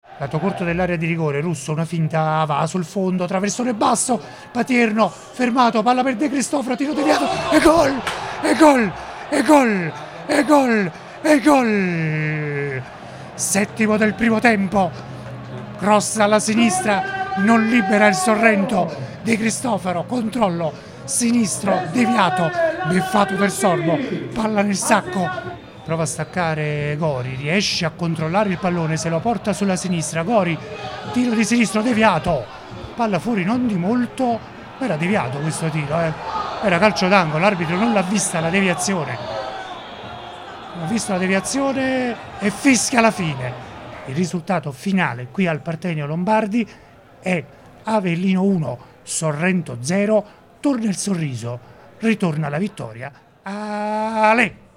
PODCAST | RIASCOLTA L’ESULTANZA